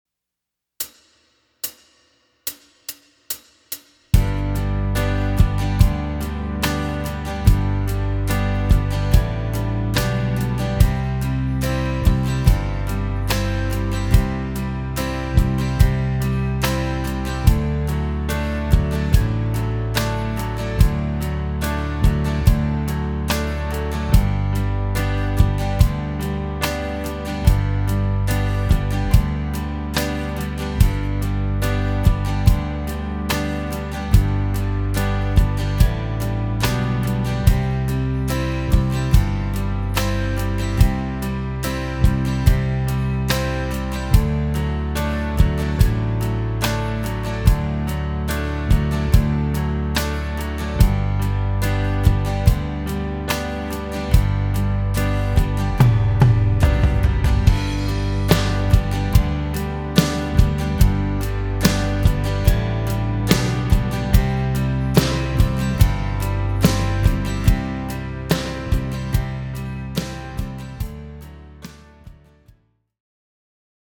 Track Sample, G Major: